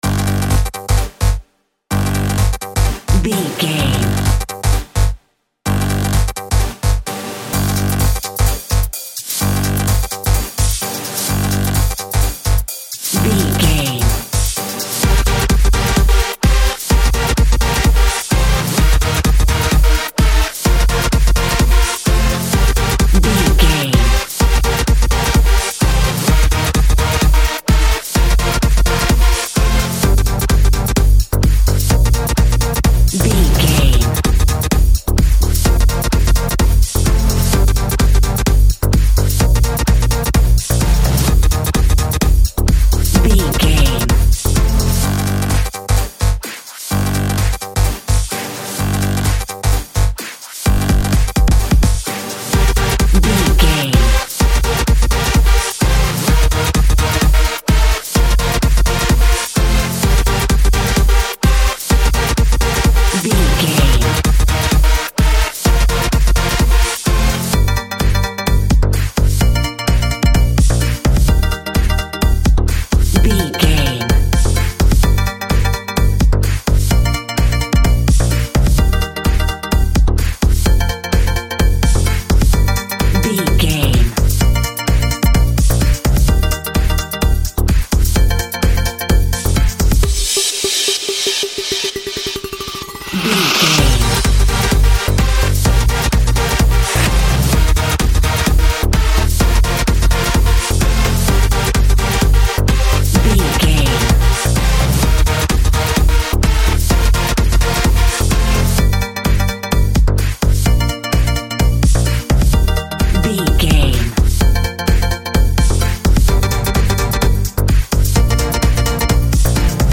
Club Music Cue.
Aeolian/Minor
Fast
groovy
dark
futuristic
driving
energetic
synthesiser
drum machine
house
electro dance
techno
trance
synth bass
upbeat